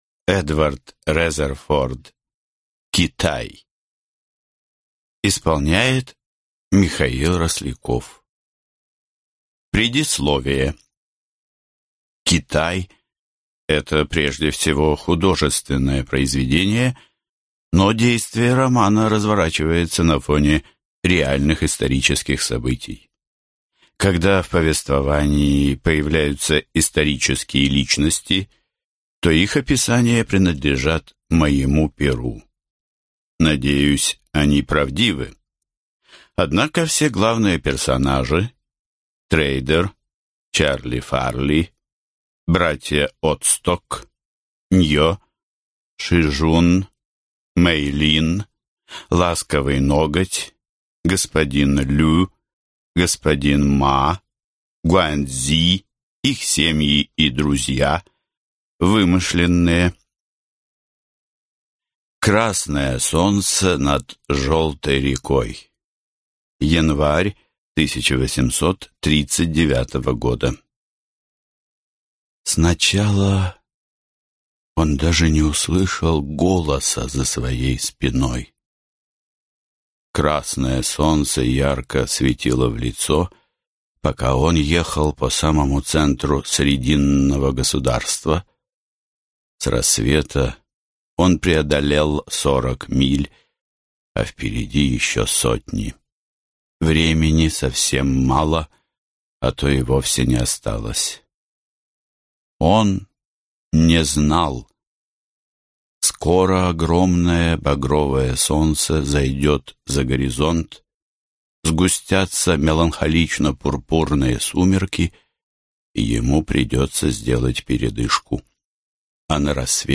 Аудиокнига Китай | Библиотека аудиокниг